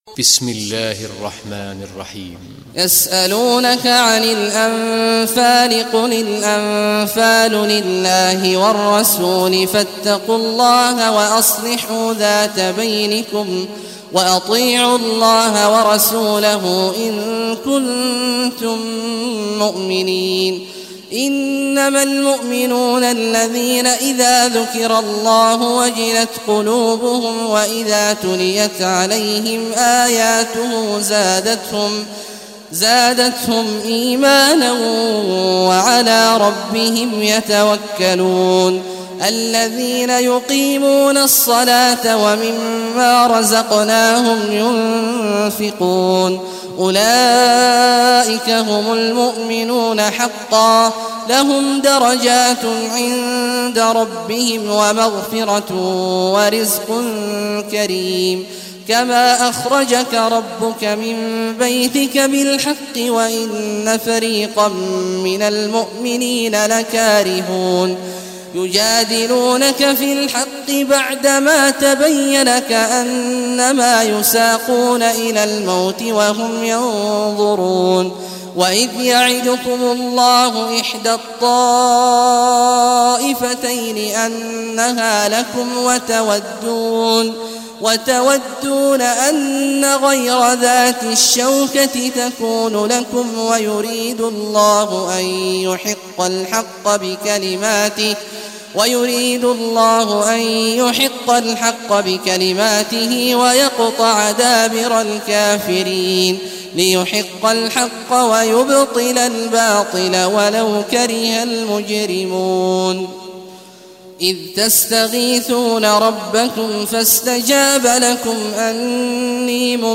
Surah Anfal Recitation by Sheikh Awad al Juhany
Surah Anfal, listen or play online mp3 tilawat / recitation in Arabic in the beautiful voice of Sheikh Abdullah Awad al Juhany.